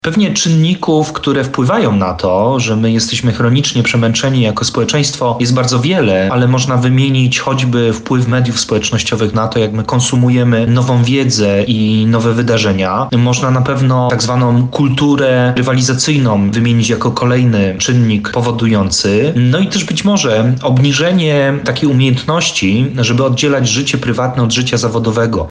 O tym jakie mogą być tego przyczyny mówi trener mentalny